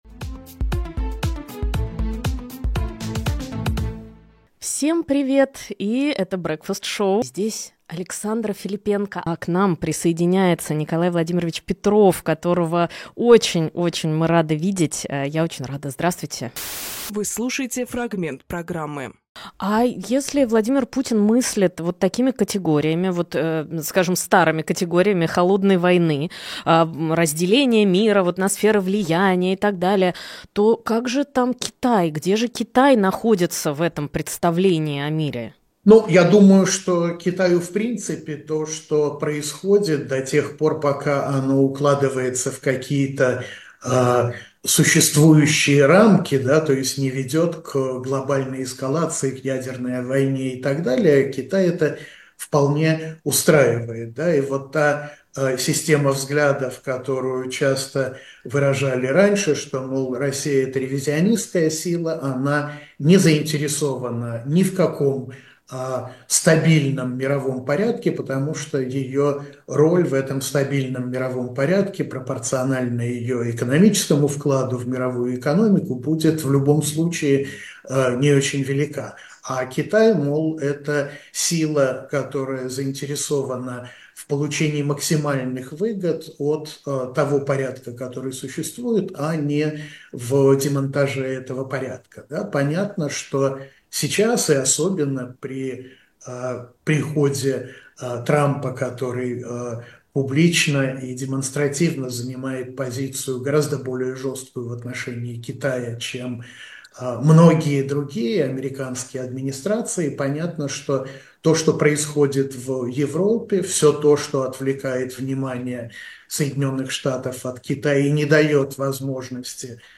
Фрагмент эфира от 12.01